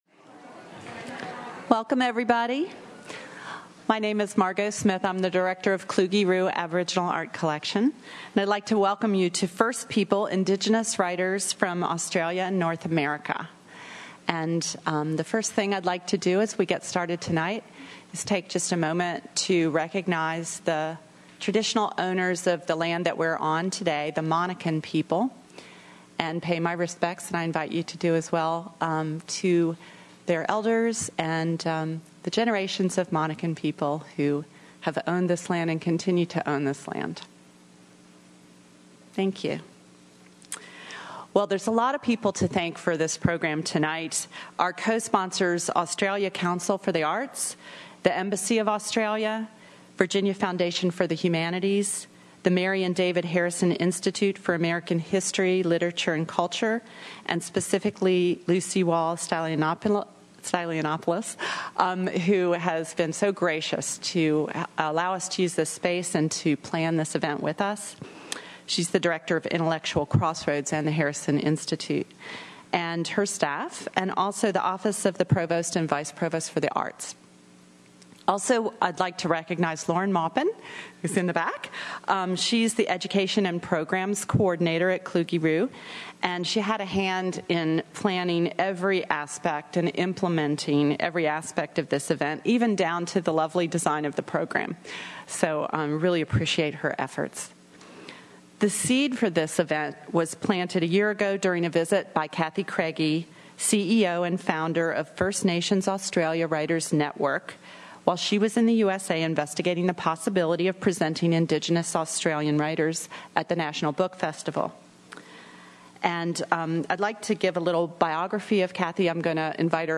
Six Indigenous Australian writers and two Indigenous American writers read from and discussed their work in an event titled First People: Indigenous Writers from Australia and North America on September 8, 2015 at the Harrison Institute Auditorium.
Each writer spent seven minutes reading from a work of their choice.
Questions were also taken from the audience.